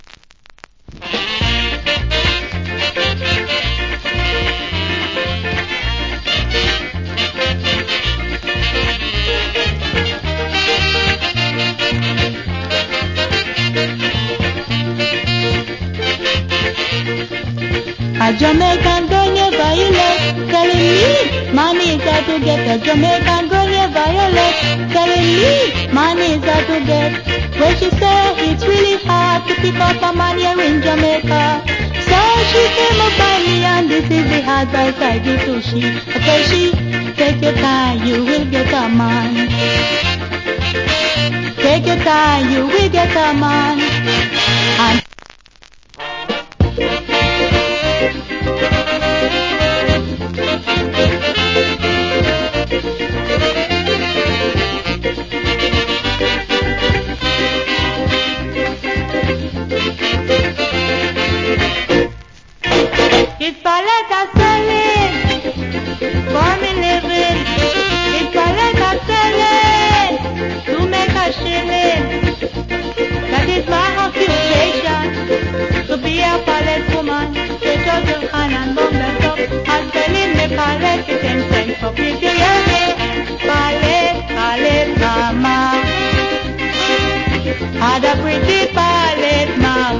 Nice Female Calypso Vocal.